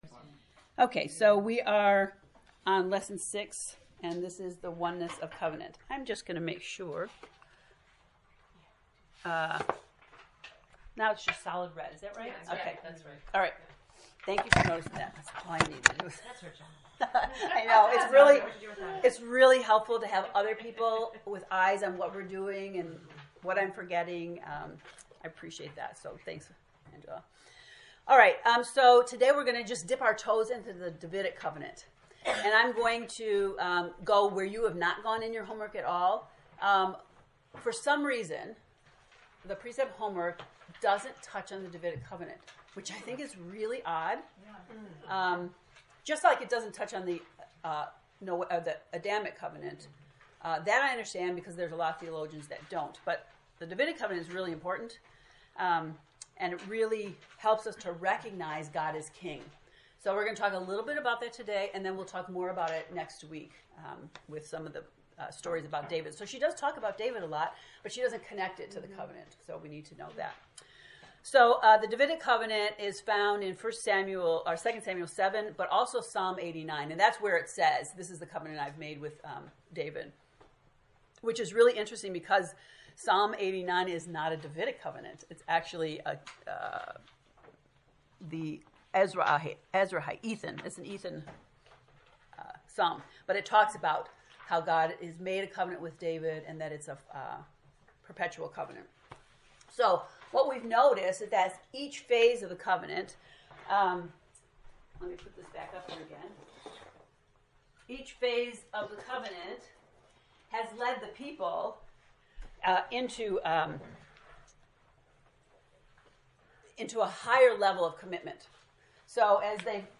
COVENANT lecture 6
To listen to the lecture, “The Oneness of Covenant,” click below: